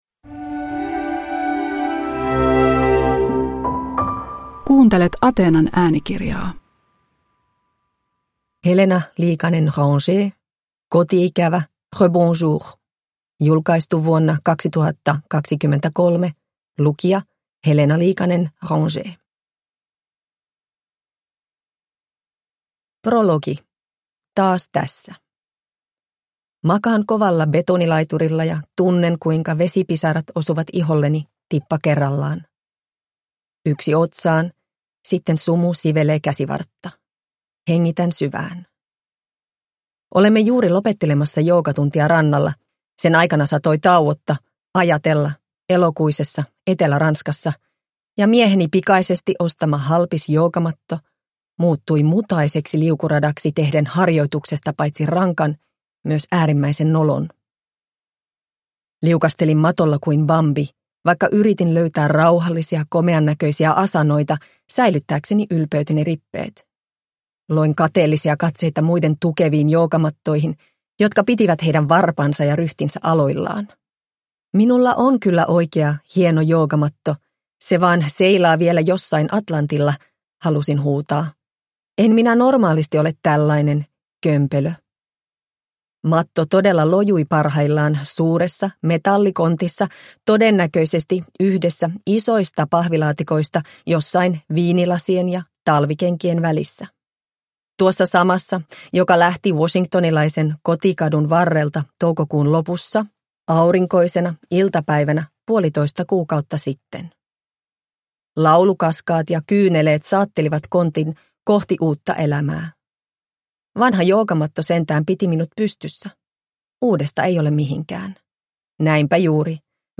Koti-ikävä, rebonjour – Ljudbok – Laddas ner